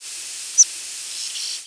Learning flight calls
Warblers generally have the shortest and highest-pitched flight calls.
They sound somewhat like a single cricket chirp. Among the warbler flight calls, many fall into two categories: those that are buzzy (such as the "dziit" of a Yellow Warbler) and those that are more pure-toned (such as the
"seemp" of a Palm Warbler).